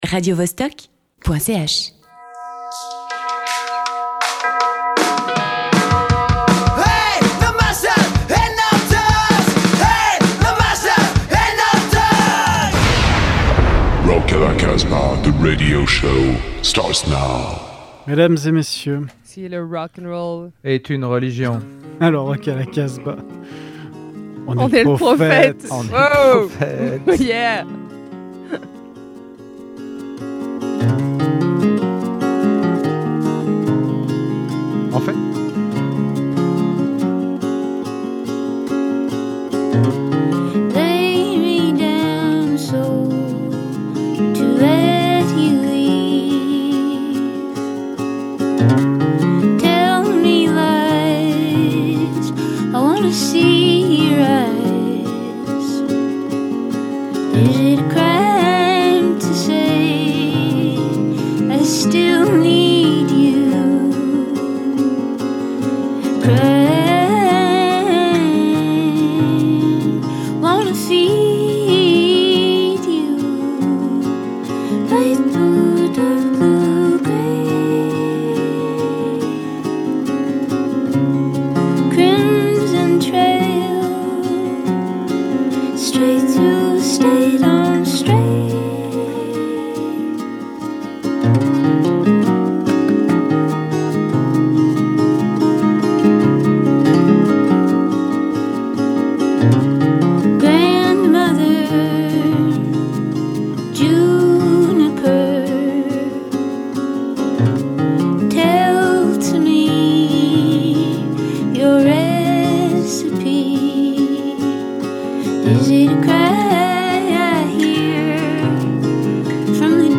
Si tu aimes les sonorités tordues, la tensions palpables ou la vague surf psychédélique de l’ouest alors ce programme est pour toi.
Elle est réalisée par des accros de rock underground, basée à Valence dans la Drôme.